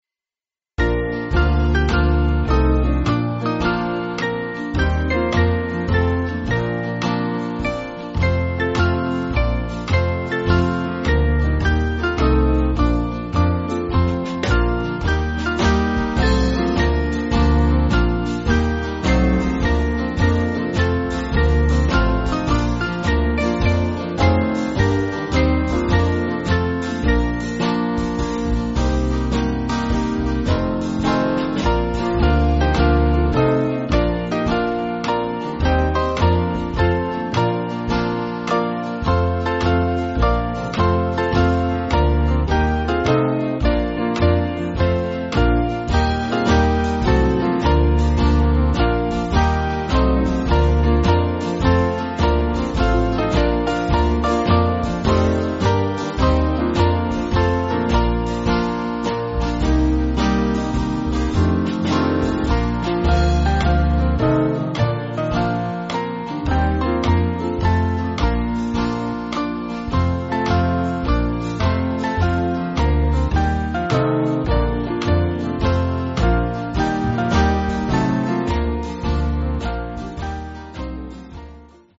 Swing Band